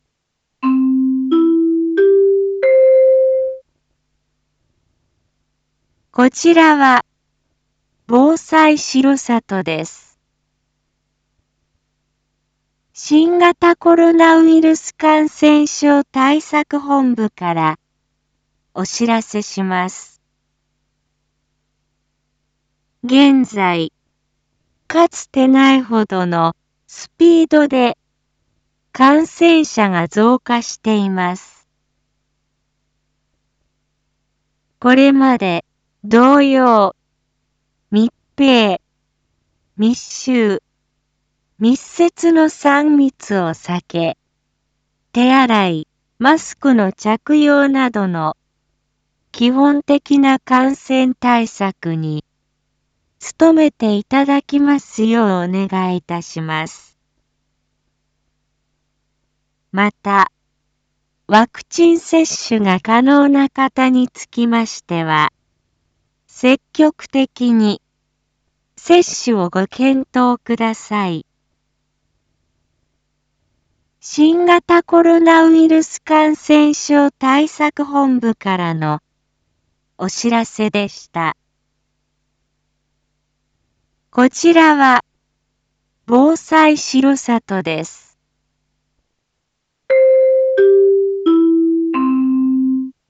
一般放送情報
Back Home 一般放送情報 音声放送 再生 一般放送情報 登録日時：2022-08-22 19:01:39 タイトル：新型コロナウイルス対策本部からのお知らせ インフォメーション：こちらは防災しろさとです。